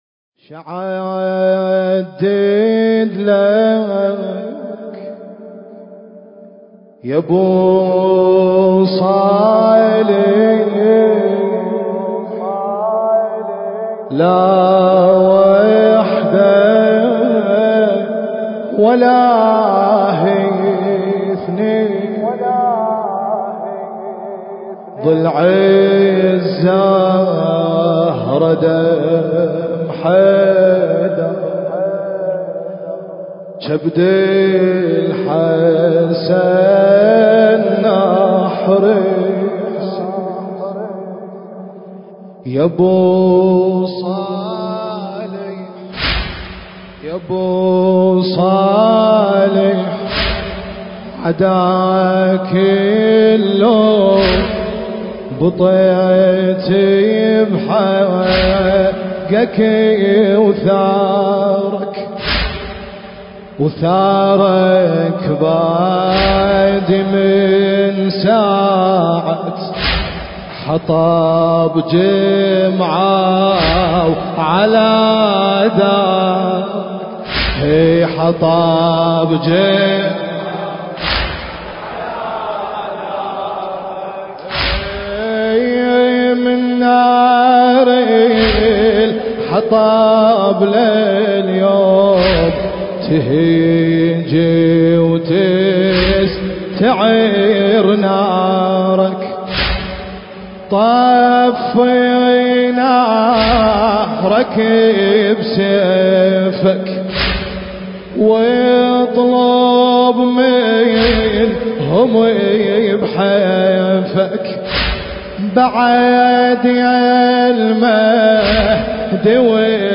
حسينية البقالين – هيئة شباب علي الأصغر (عليه السلام) – كربلاء المقدسة